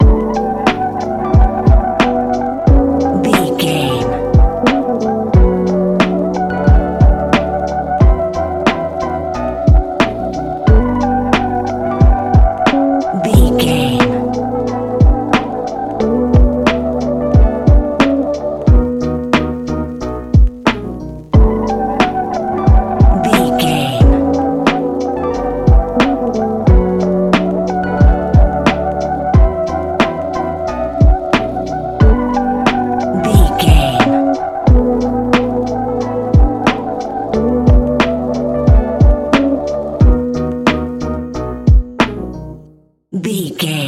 Ionian/Major
B♭
laid back
Lounge
sparse
chilled electronica
ambient